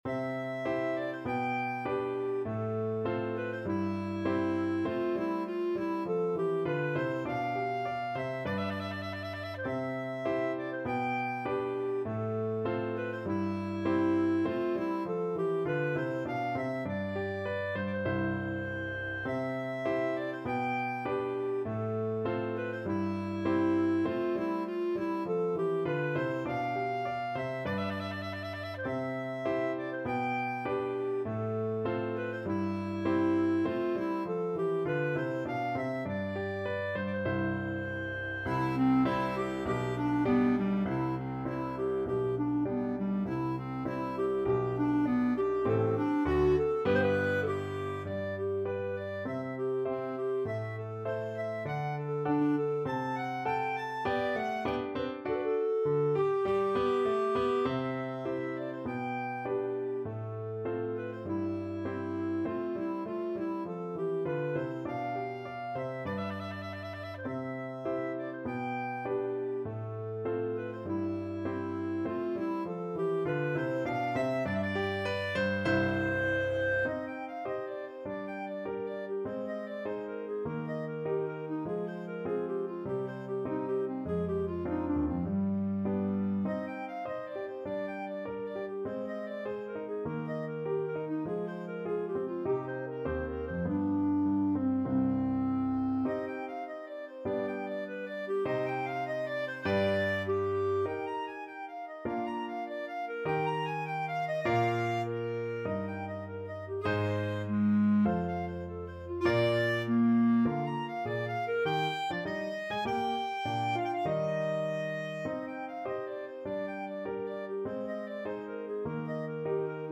Clarinet
C major (Sounding Pitch) D major (Clarinet in Bb) (View more C major Music for Clarinet )
~ = 100 Allegretto
2/2 (View more 2/2 Music)
Classical (View more Classical Clarinet Music)